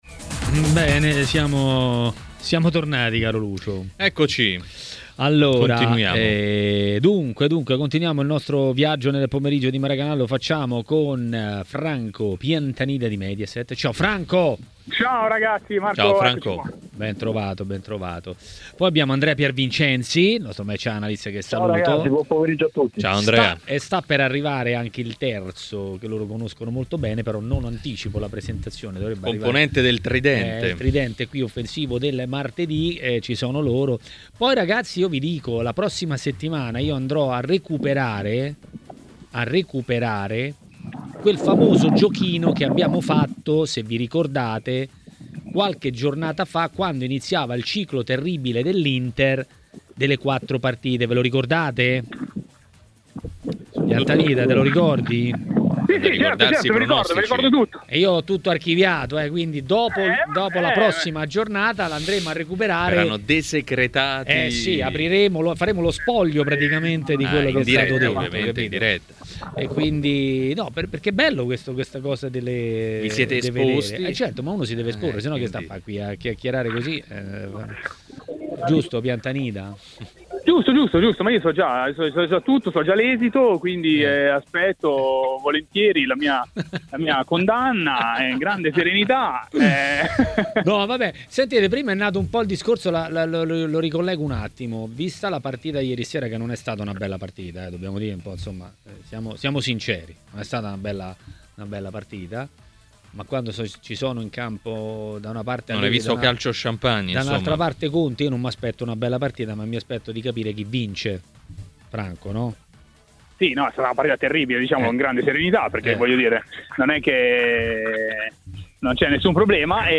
Ospite di Maracanà, nel pomeriggio di TMW Radio